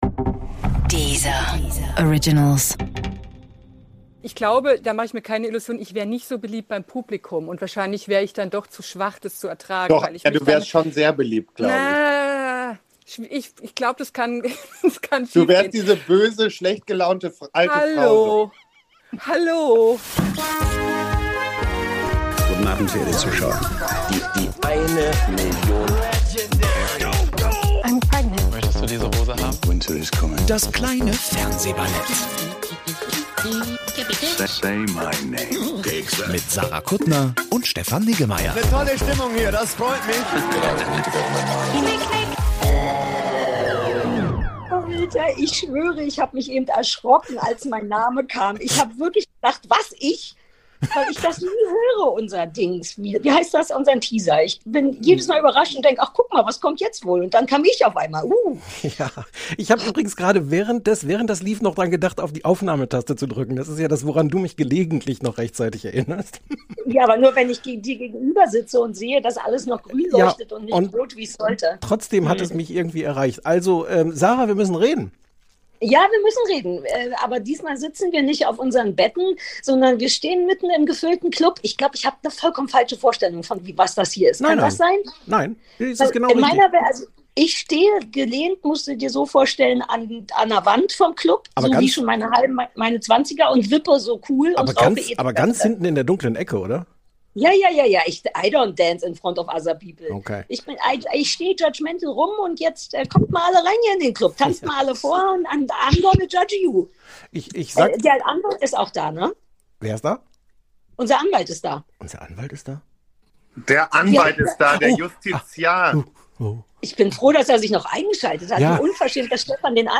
Wir reden live auf Clubhouse über die Frage: Ist Trash-TV der Impfstoff für die Seele?